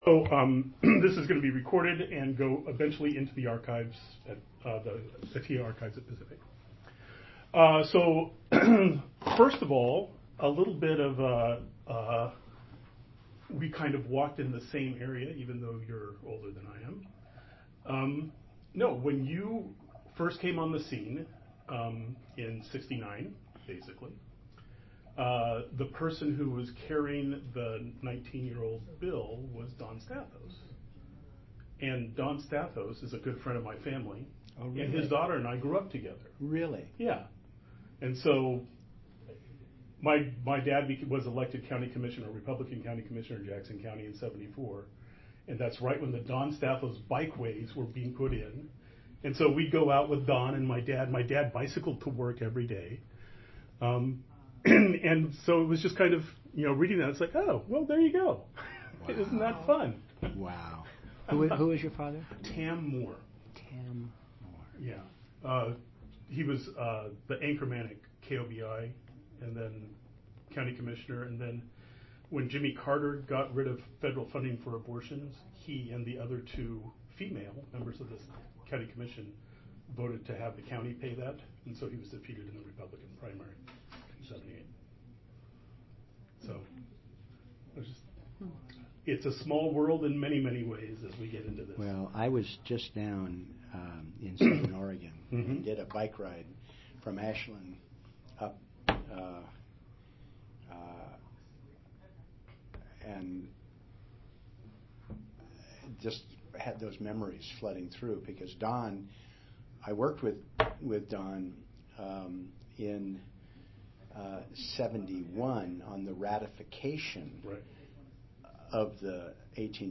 d0e5de2f88c3c6a3b68d0b36627f3eb2c40c0fac.mp3 Title Earl Blumenauer interview on Atiyeh Description An interview of US Representative Earl Blumenauer on the topic of Oregon's Governor Vic Atiyeh, recorded on June 8, 2015.